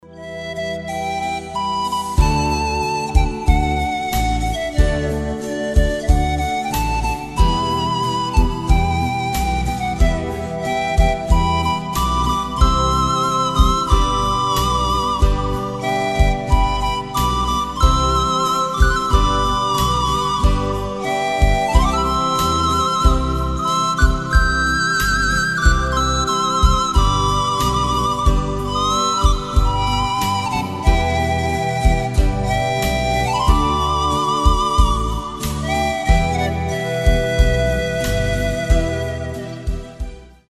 красивые
без слов
релакс
Cover
расслабляющие
Флейта
испанские
нежные
New Age